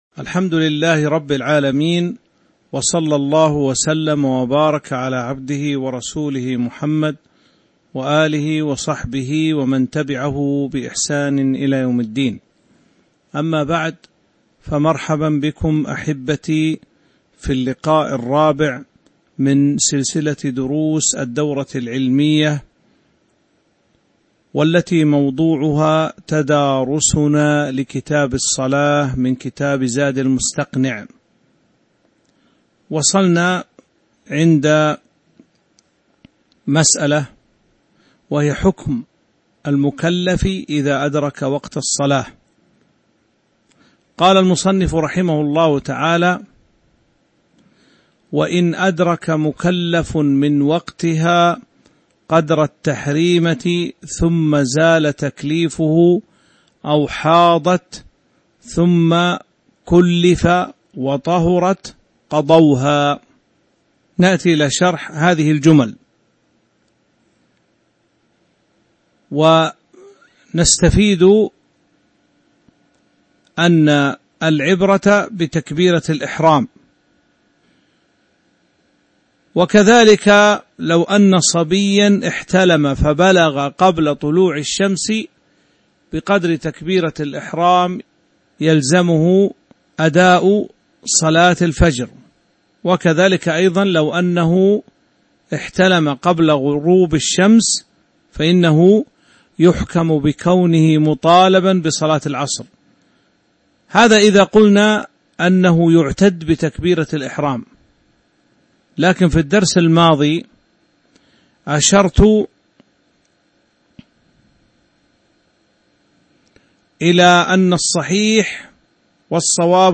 تاريخ النشر ٢٣ ذو الحجة ١٤٤٢ هـ المكان: المسجد النبوي الشيخ